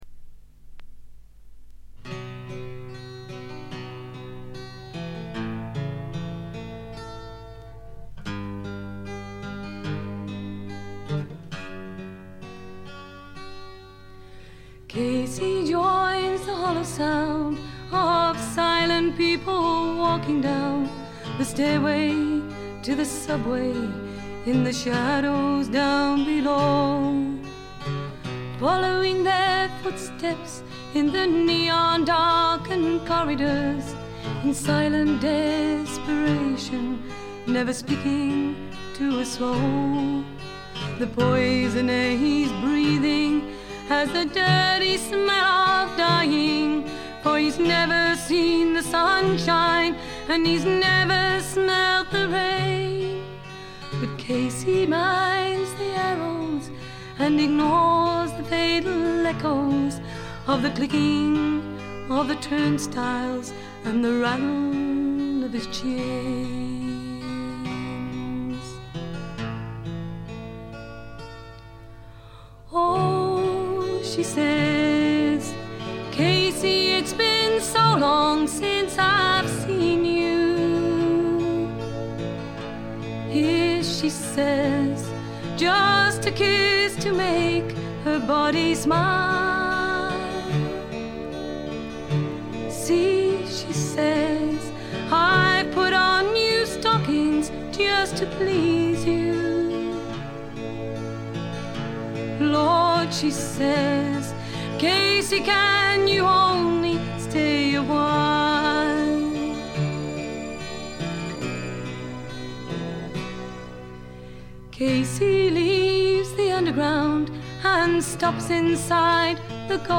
フィメールを含む4人組。
試聴曲は現品からの取り込み音源です。
acoustic guitar, fiddle, vocals
Recorded at Mid Wales Sound Studio June 1976